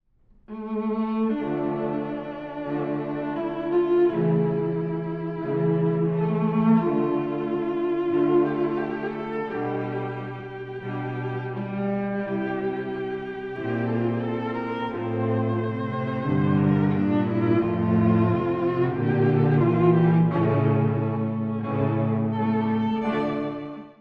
激情的な楽章。
ビオラから始まる、独白のごとき主題が有名です。
感情の昂ぶりがダイレクトに感じられます。